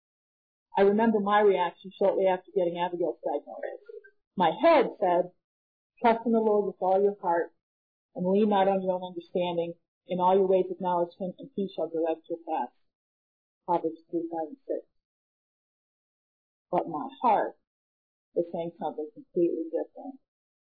I was also given the privilege of presenting a lesson for our Ladies Inspiration Day at our church in April, 2004. The subject was dealing with struggles, and I used examples from the book of Job as well as my own experiences to share some of what God has taught me about grief and suffering.